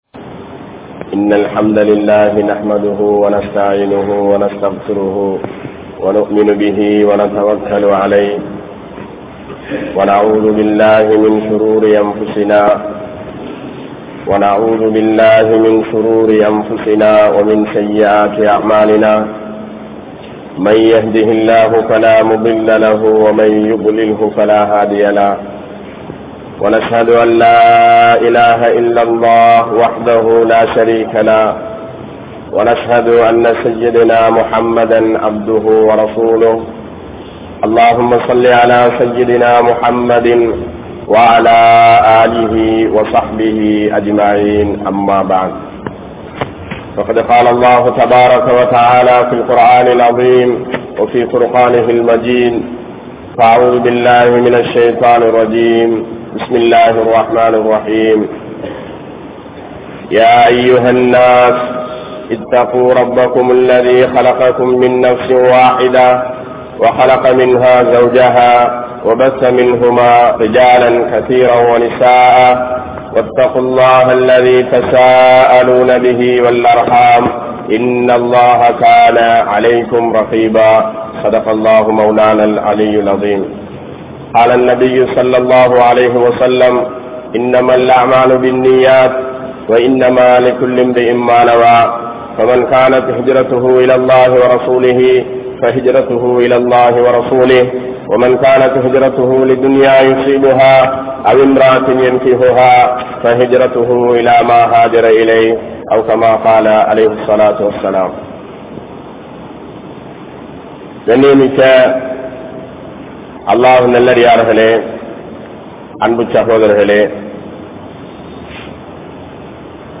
Islam Koorum Suththam (இஸ்லாம் கூறும் சுத்தம்) | Audio Bayans | All Ceylon Muslim Youth Community | Addalaichenai
PachchaPalli Jumua Masjidh